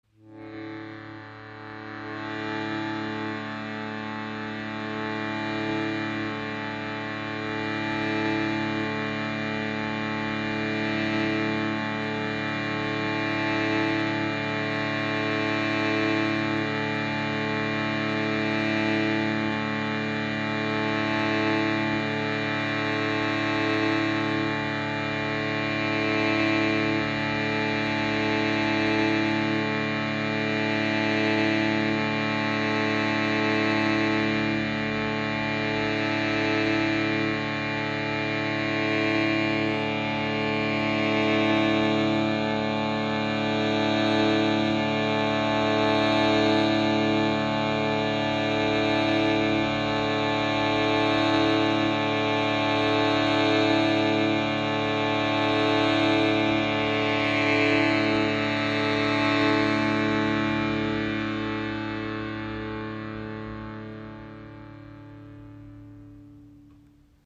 Teakholz Shruti Box A3 bis A4 | Borduninstrument in 440 Hz und 432 Hz • Raven Spirit
Die Shruti Box entführt Dich in ein Meer aus tragendem Klang. Ziehe am Blasebalg und erlebe, wie ein harmonischer Bordun den Raum hält, während Deine Stimme frei erklingt.